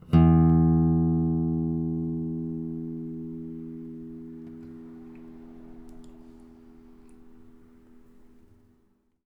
bass-15.wav